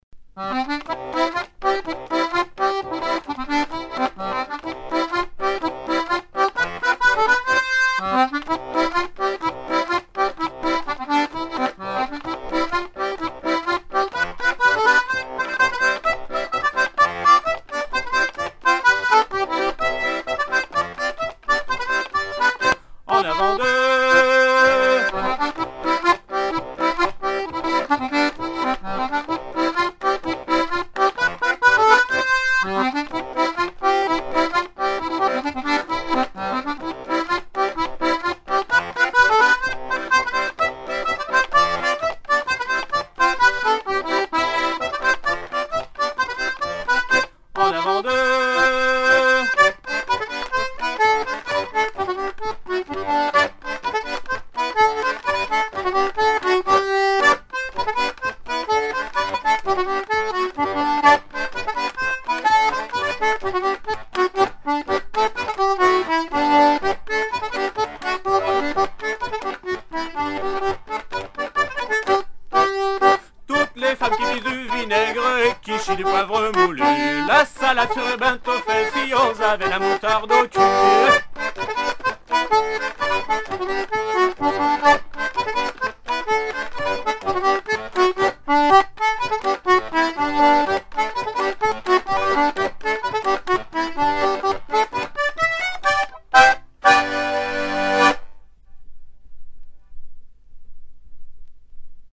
recueillis vers 1978 à Pleudihen, PlouPer-sur-Rance et Saint-Suliac
danse : branle : avant-deux
Pièce musicale éditée